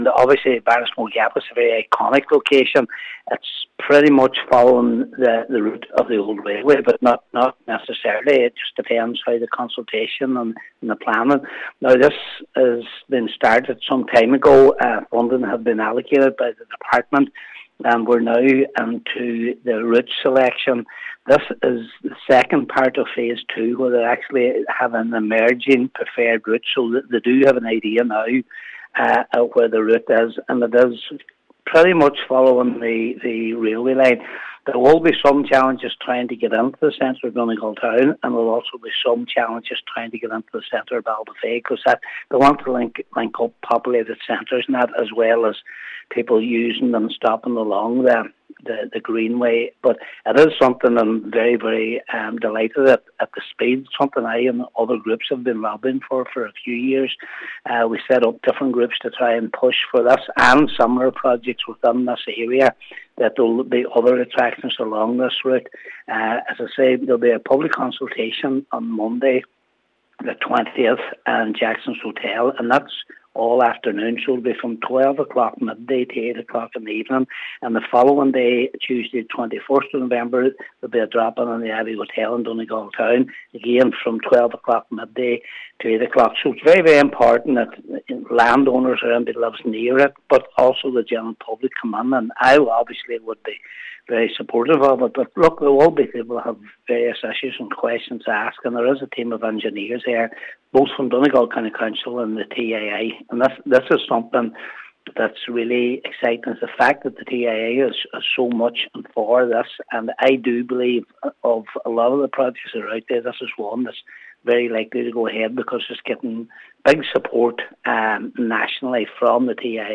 Councillor Patrick McGowan says that the project has gained national support from the TII and the council: